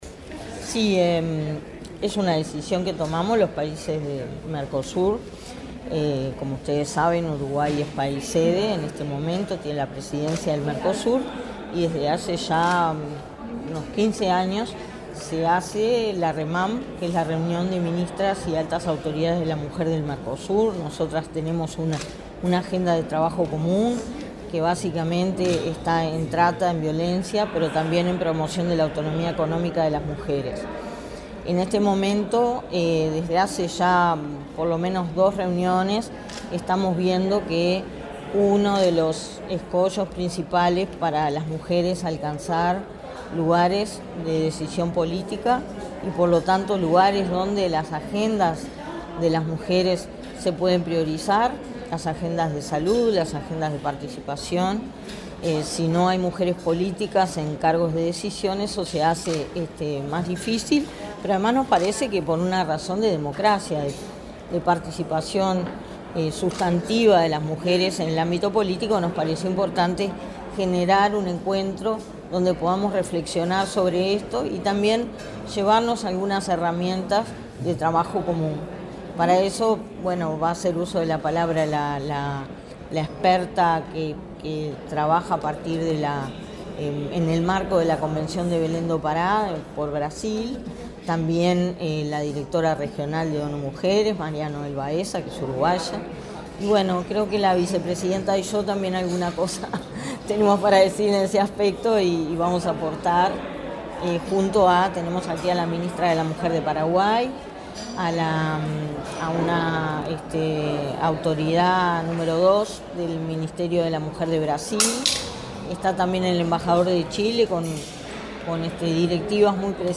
Este viernes 29 en el Palacio Legislativo, la directora del Instituto Nacional de las Mujeres (Inmujeres), Mónica Bottero, dialogó con la prensa,